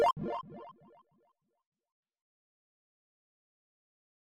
Refactor upgrade logic to include sound effects on purchases and upgrades, improving overall gameplay immersion. 2025-03-30 14:24:53 -04:00 102 KiB Raw History
upgrade.mp3